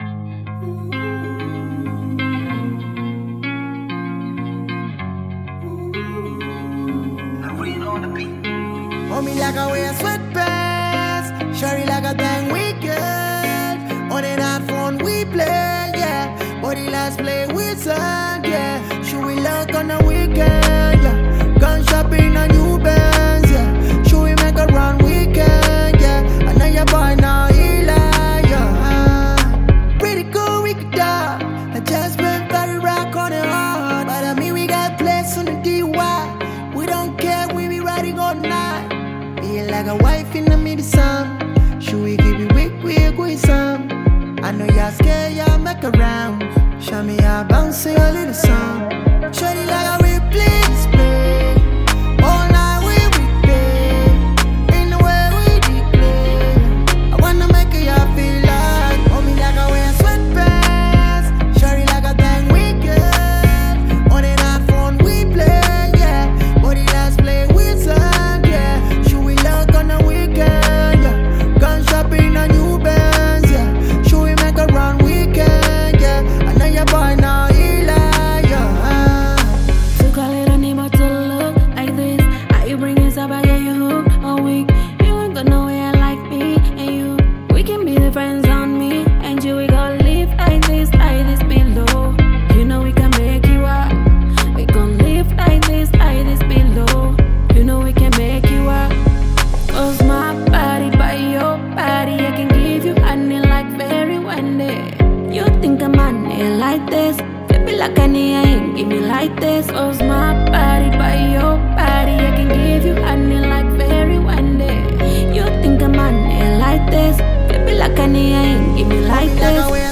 Bongo Flava
Tanzanian Bongo Flava artists, singers, and songwriters